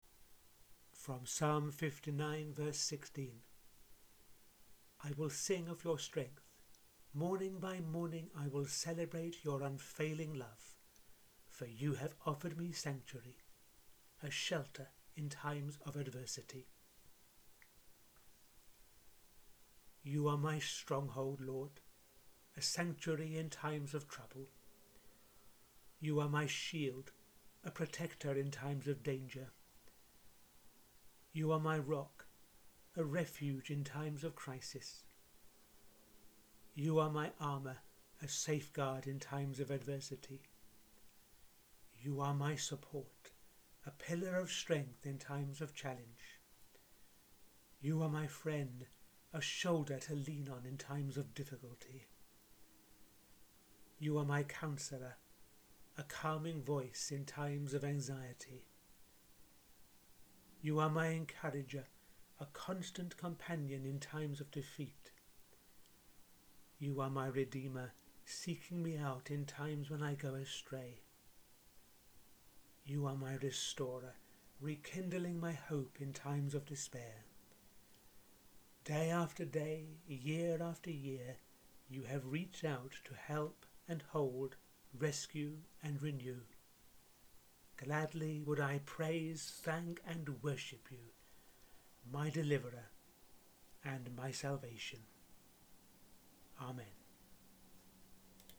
Another prayer for your daily devotions.